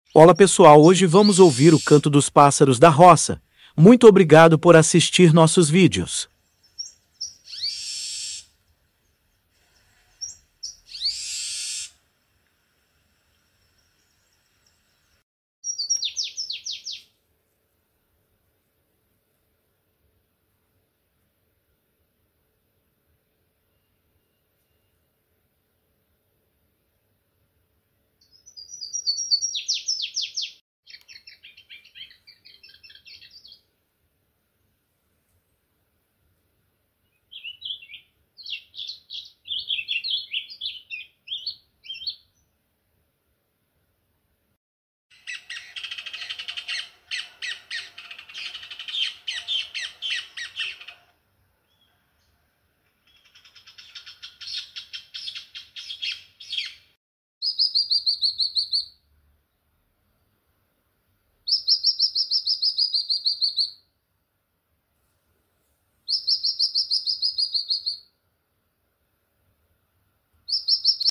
canto dos pássaros da roça sound effects free download